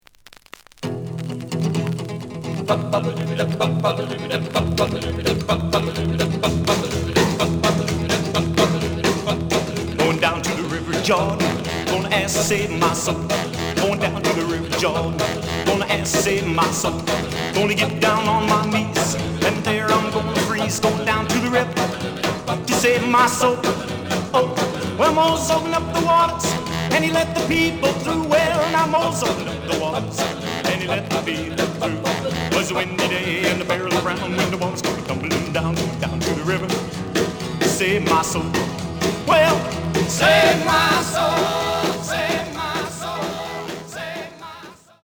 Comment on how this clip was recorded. The listen sample is recorded from the actual item. Slight edge warp.